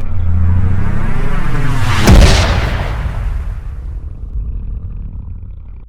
gravi_blowout6.ogg